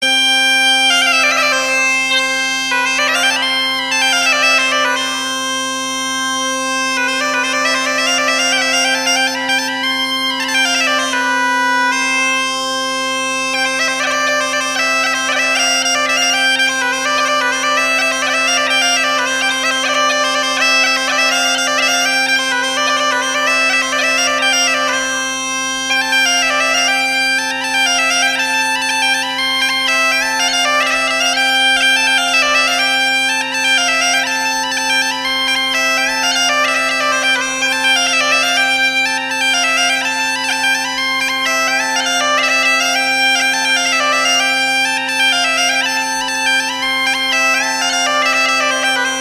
GAITA Consta de un tubo con una lengüeta doble por el que se sopla, una bolsa u odre que retiene el aire (donde están los agujeros, que se tapan con los dedos).